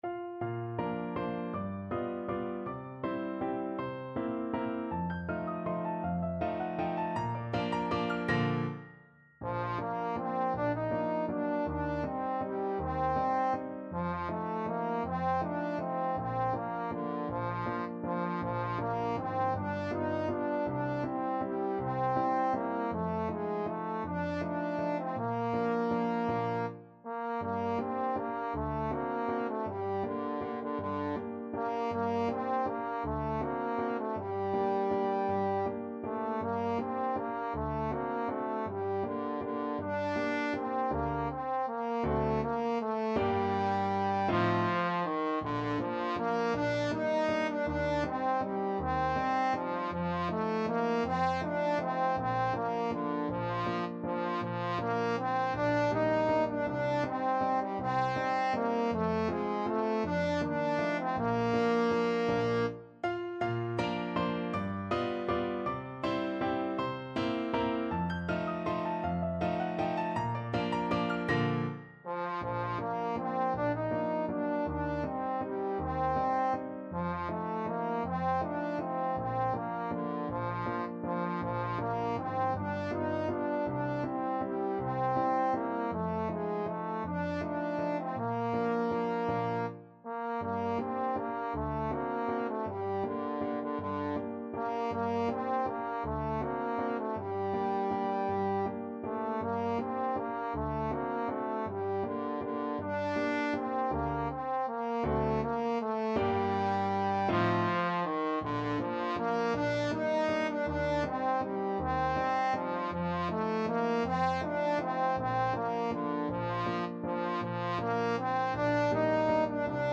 Trombone
Bb major (Sounding Pitch) (View more Bb major Music for Trombone )
3/4 (View more 3/4 Music)
~ = 160 Tempo di Valse
D4-Eb5
Traditional (View more Traditional Trombone Music)